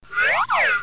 00184_Sound_boing.mp3